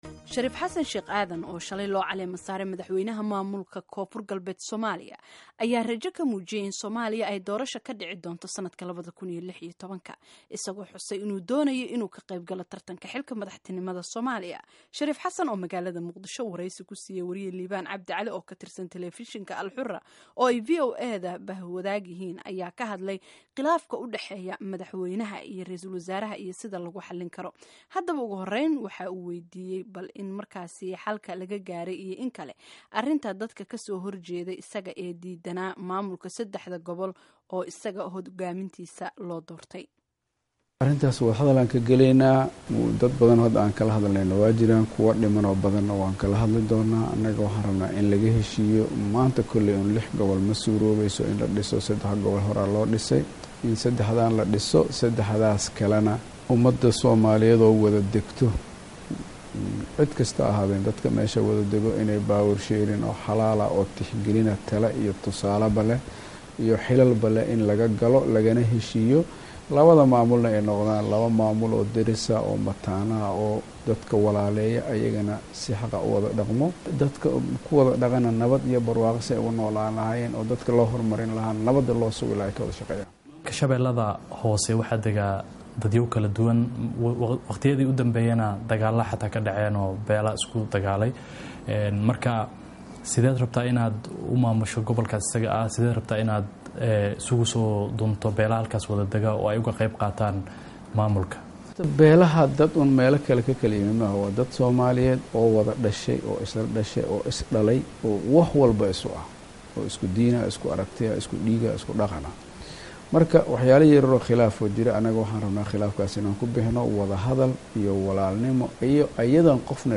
Wareysi: Shariif Xasan Sh. Aadan
Dhageyso Wareysiga Shariif Xasan